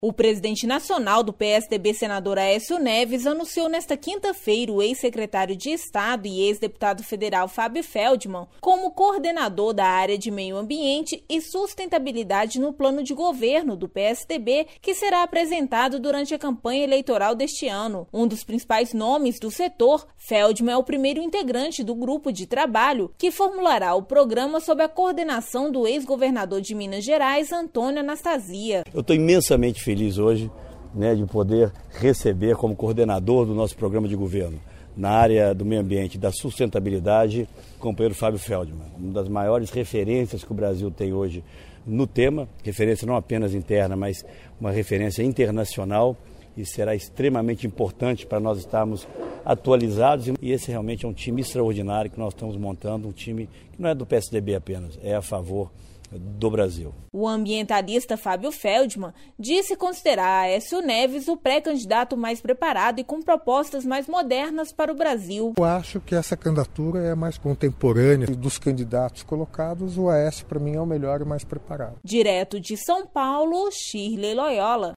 Sonora de Aécio Neves
Sonora de Fabio Feldmann
Boletim